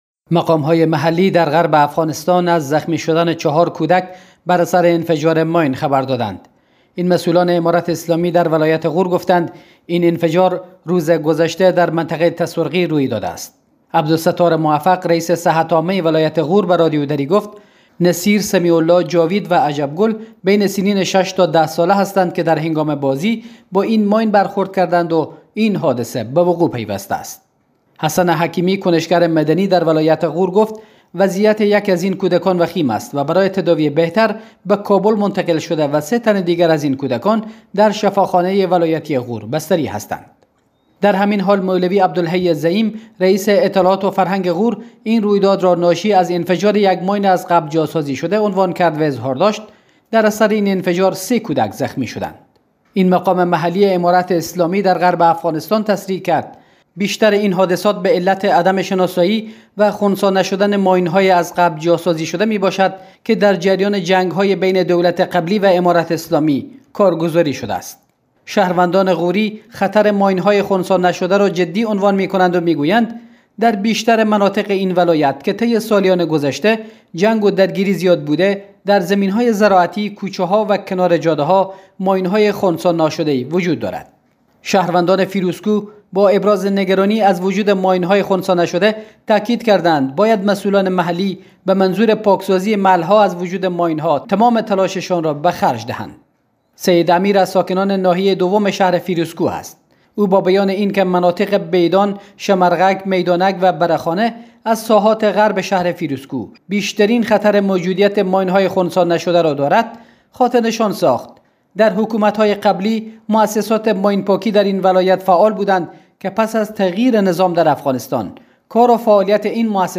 به گزارش رادیودری، عبدالستار موفق، رئیس صحت عامه ولایت غور گفت